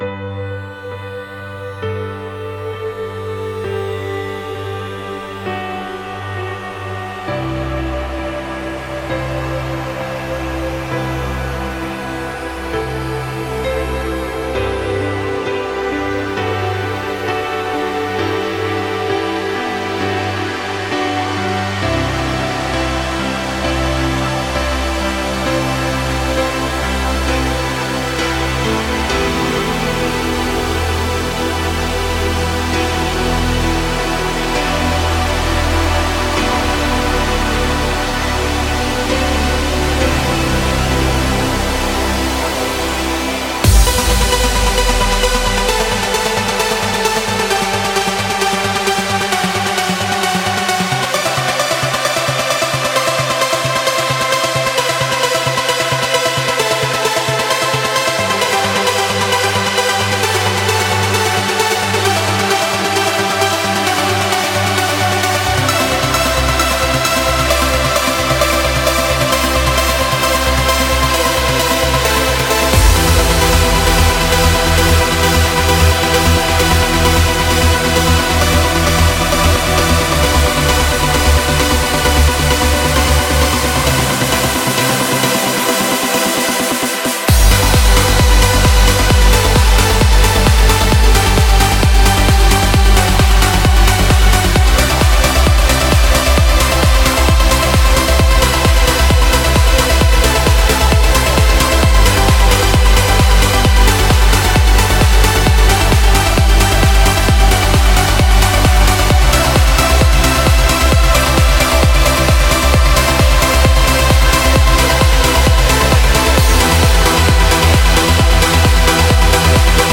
BPM17-132
Comments[NEW MILLENNIUM TRANCE]